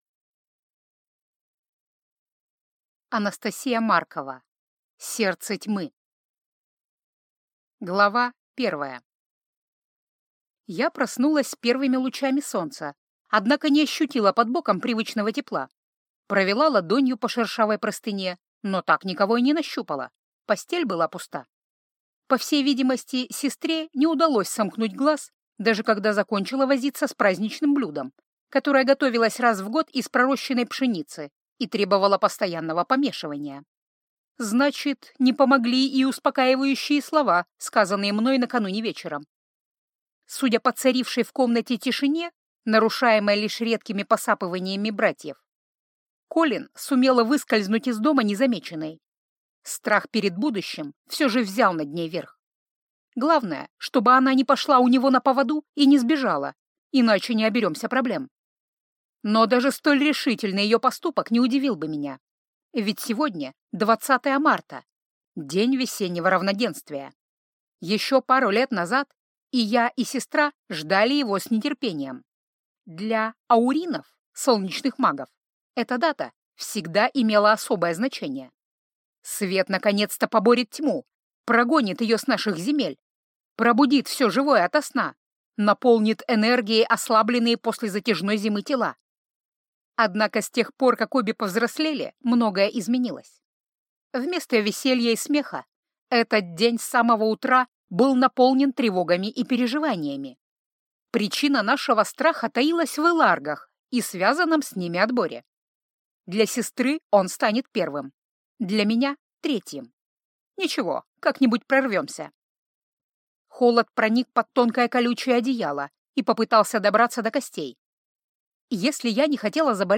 Аудиокнига Сердце тьмы | Библиотека аудиокниг